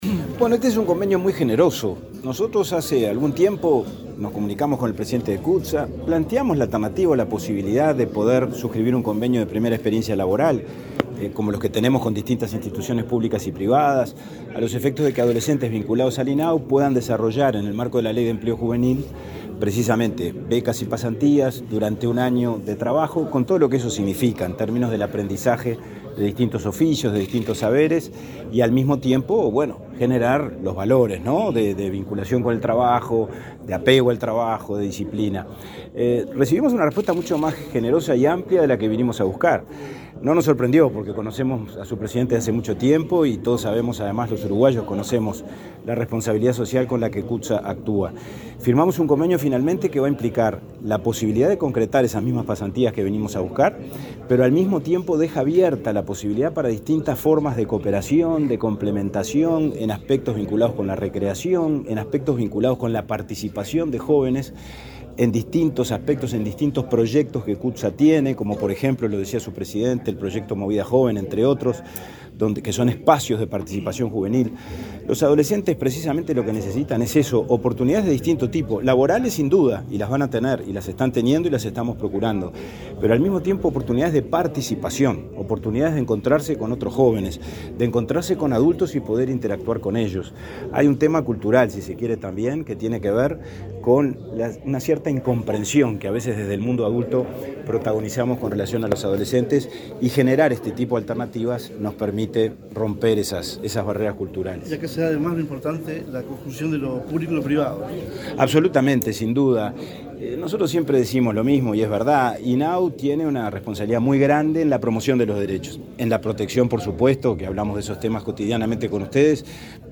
Declaraciones del presidente del INAU, Pablo Abdala
El presidente del Instituto del Niño y el Adolescente del Uruguay (INAU), Pablo Abdala, dialogó con la prensa luego de firmar un convenio con